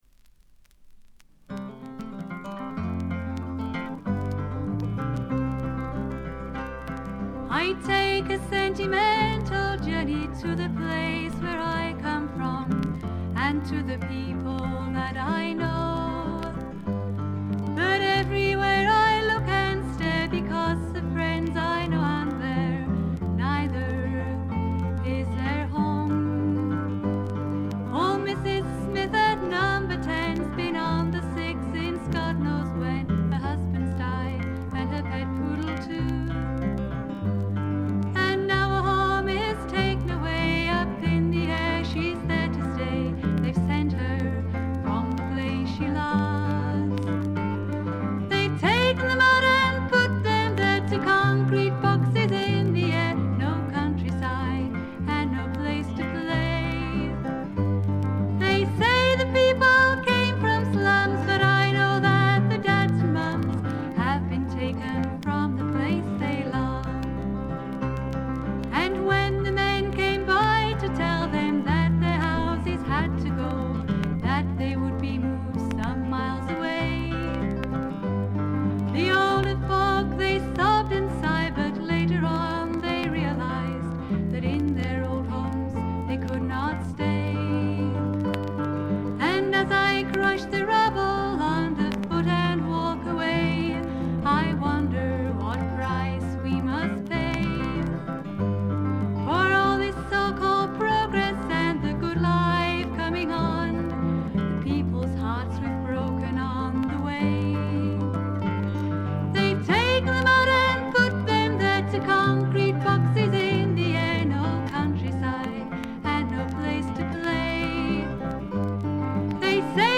常時大きめのバックグラウンドノイズ。チリプチ、プツ音等も多め大きめ。
清楚でかわいらしくしっとりとした、実に魅力ある声の持ち主で、みんなこの透きとおるヴォイスにやられてしまうんですね。
試聴曲は現品からの取り込み音源です。
Guitar, Electric Guitar
Keyboard